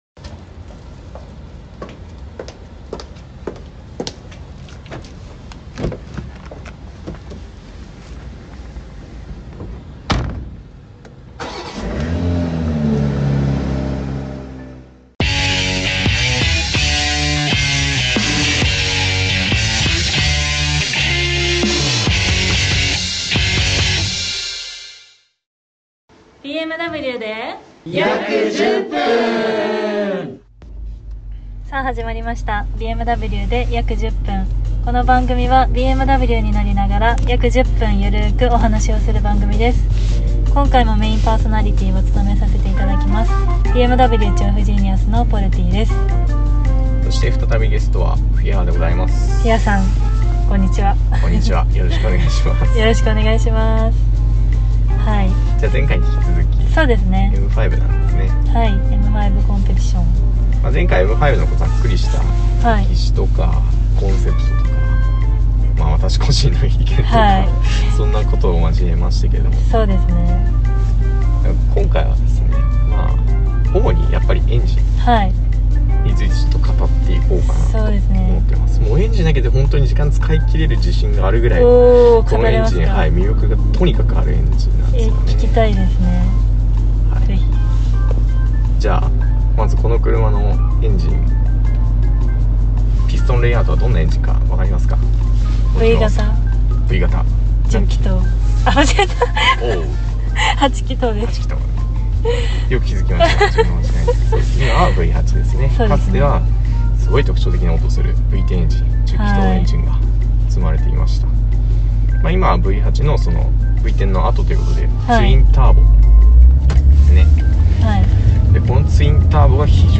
Ｍ５への愛がビンビンに伝わるお話で、迫力のＭ５の排気サウンドもしっかりと聴けます。